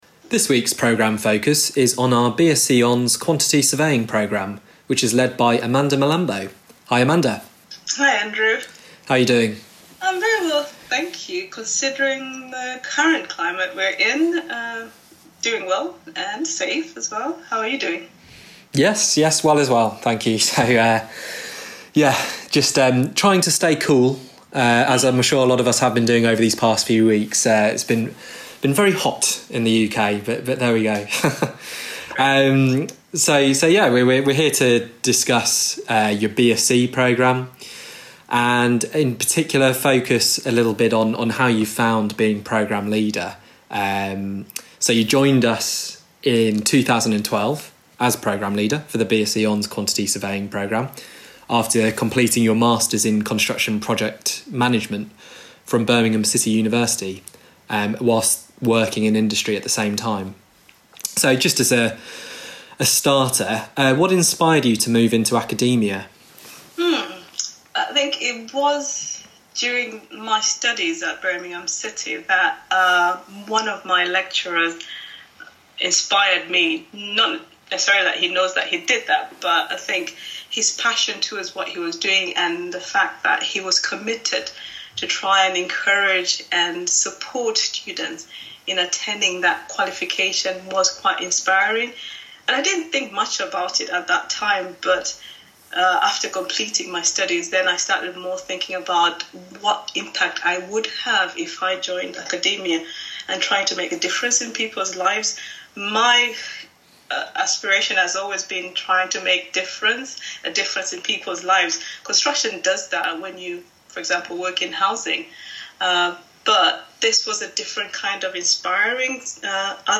Podcast: Q&A with our BSc